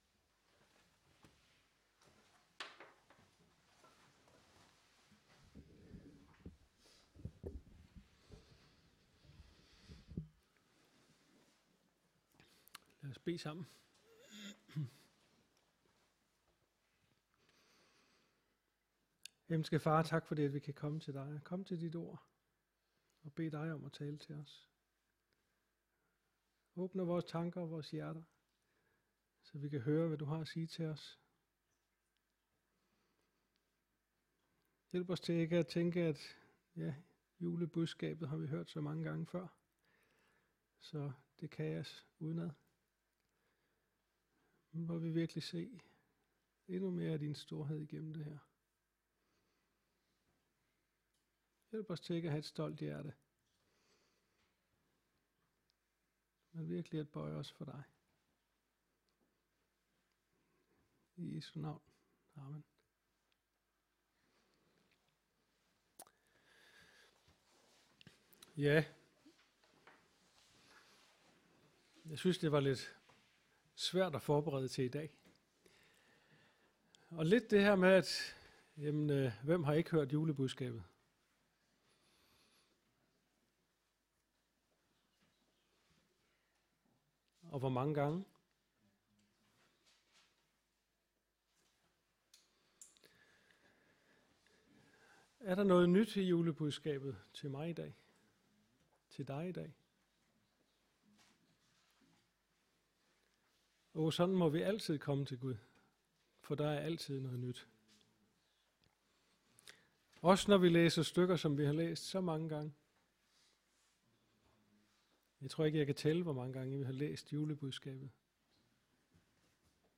Juletale 2018